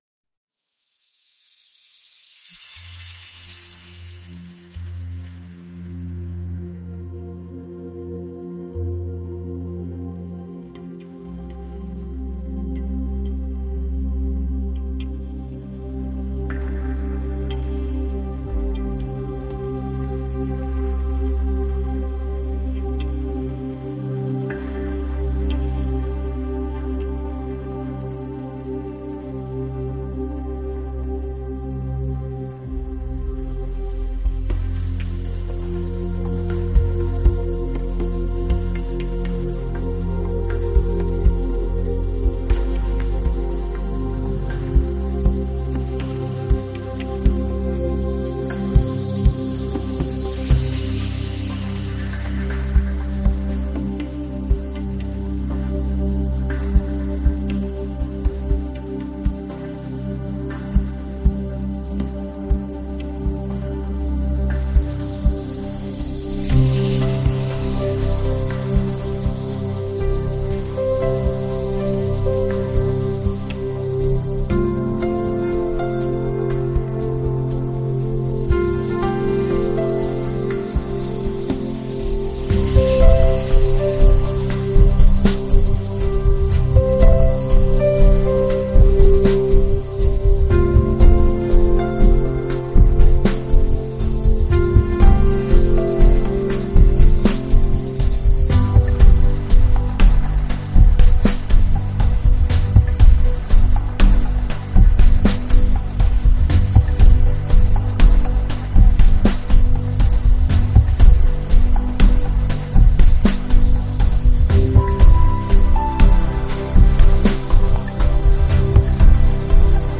・アウトロは、徐々に音がフェードアウトし、シンセサイザーのパッドと自然音が残ります。